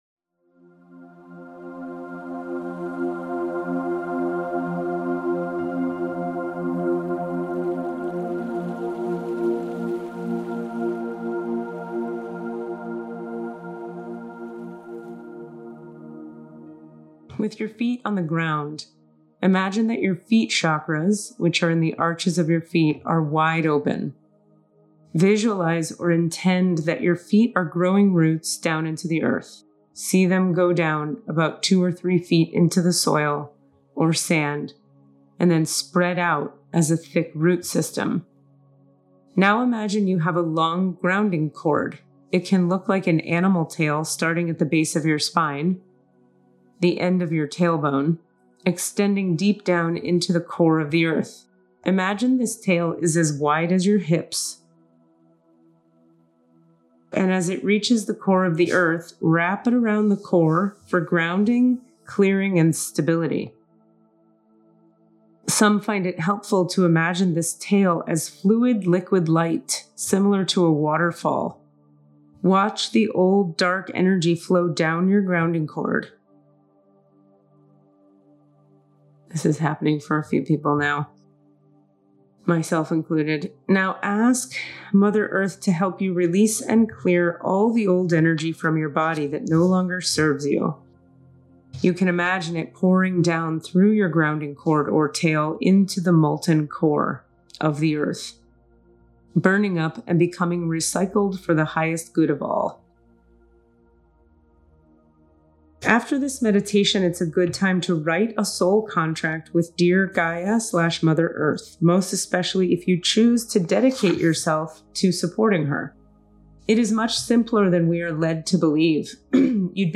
Grounding Meditation
This is the grounding exercise from last week with about 5 minutes of continued relaxation melodies to facilitate your groundedness.